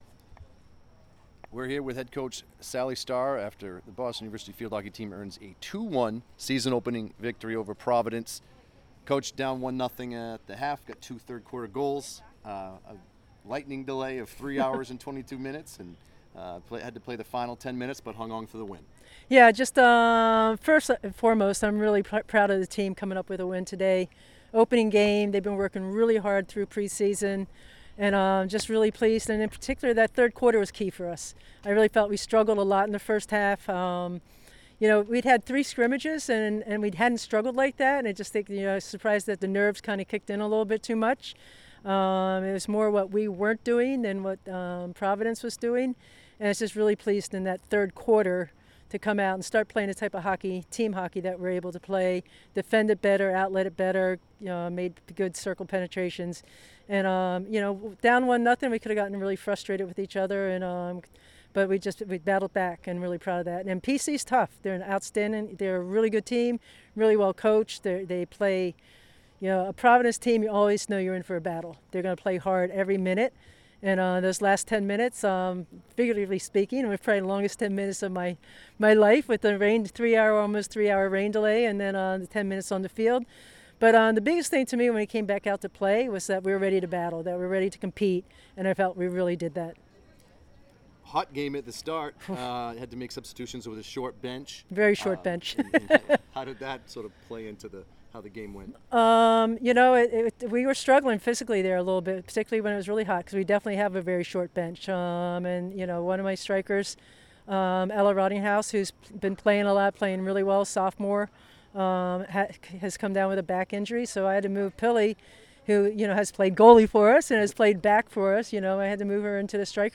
Boston University Athletics